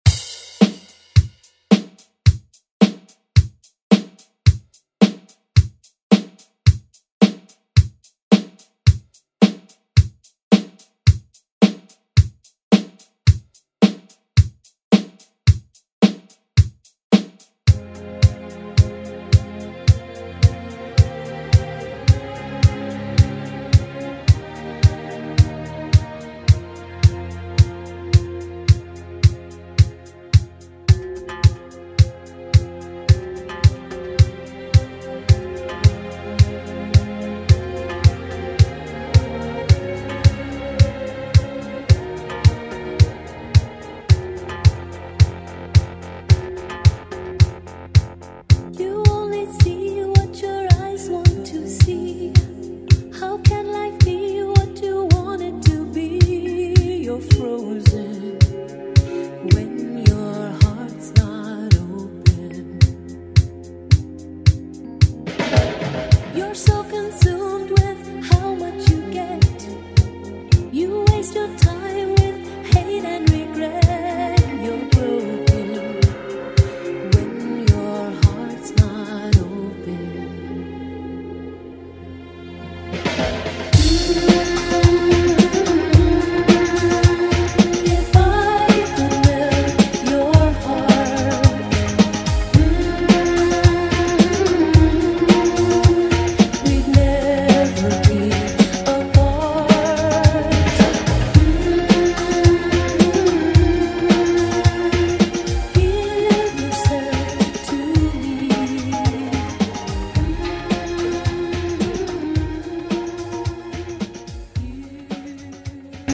Genre: RE-DRUM
Clean BPM: 105 Time